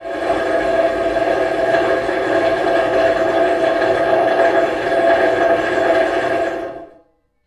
• muffled power drill.wav
Recorded outdoor in open field with a Tascam DR 40 linear PCM recorder, while drilling from within a concrete basement.
muffled_power_drill_rES.wav